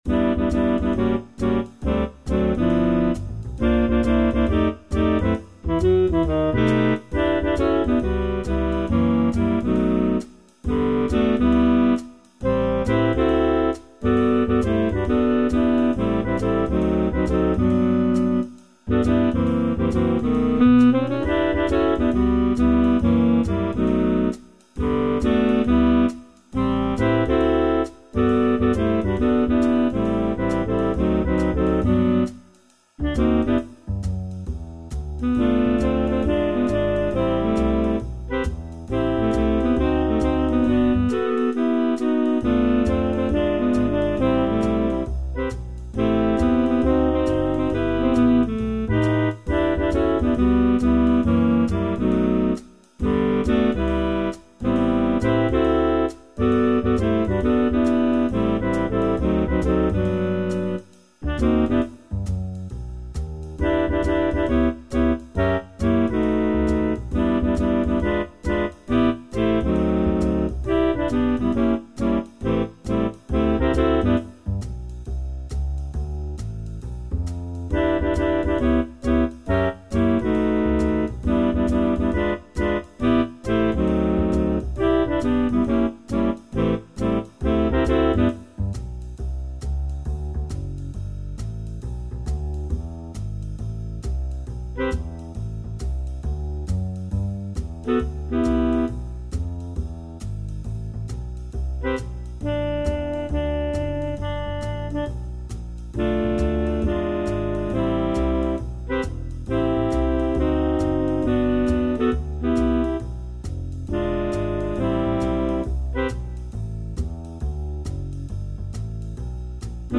SSAA met piano | SSATB | SATTB | SSAB met piano
Up tempo jazz met swingende blokakkoorden